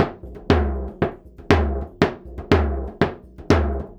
120 TOMS01.wav